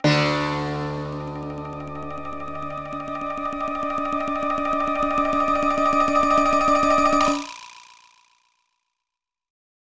Countdown music